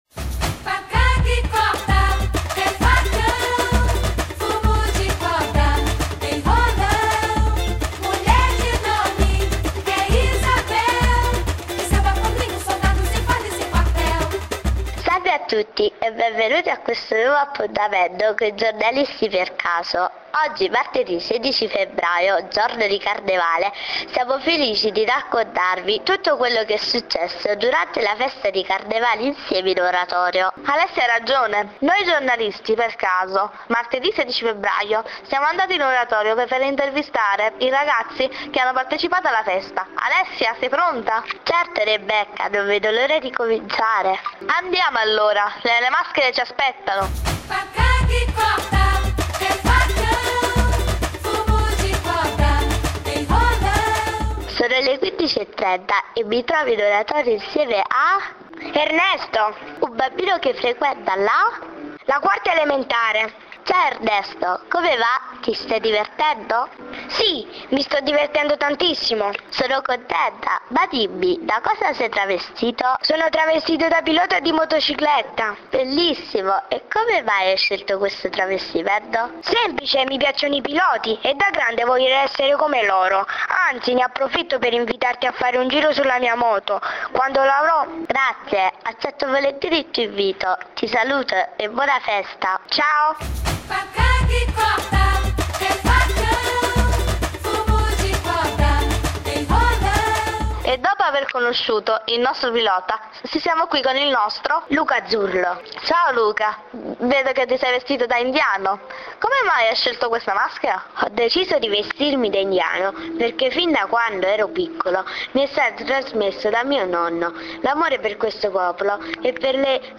Speciale Radiofonico realizzato dai Giornalisti per Caso